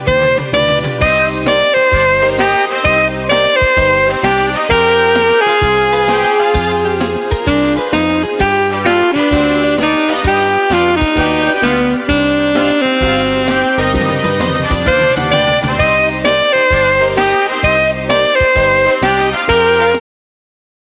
230 Простых мелодий для саксофониста